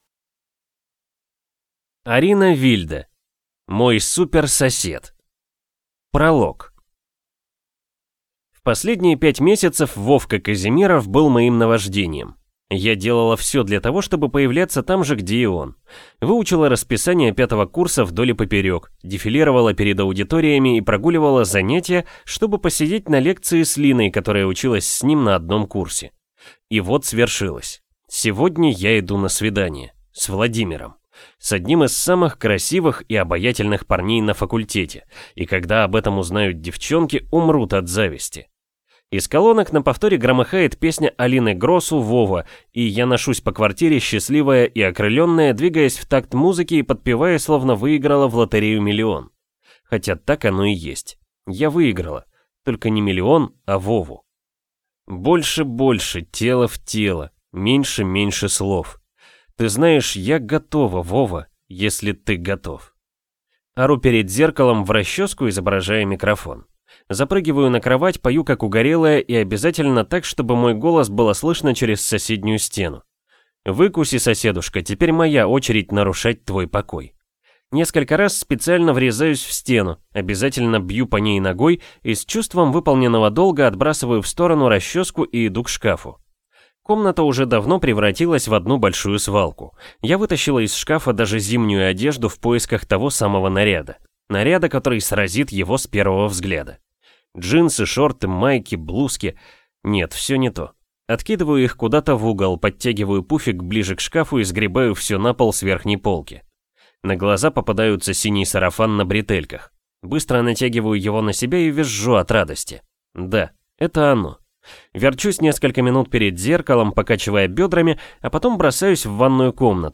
Аудиокнига Мой супер сосед | Библиотека аудиокниг